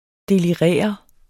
Udtale [ deliˈʁεˀʌ ]